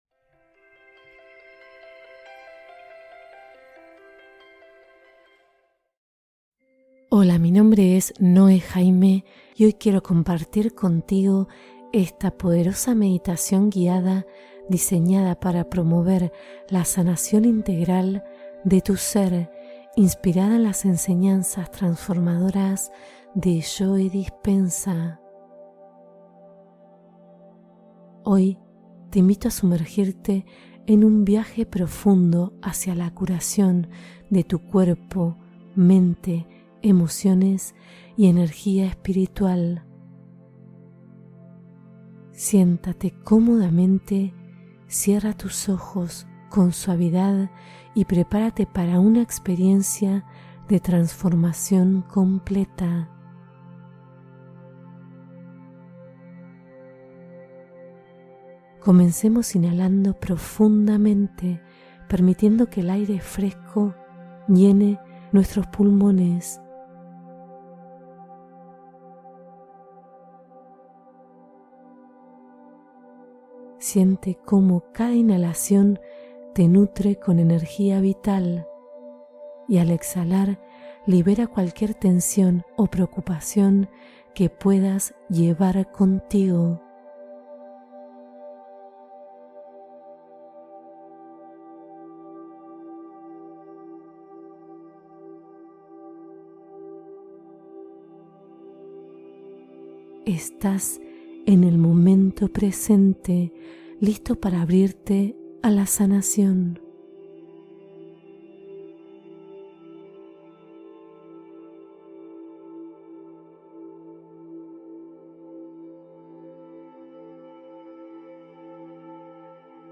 Sana tu mente y cuerpo con esta meditación curativa corta y poderosa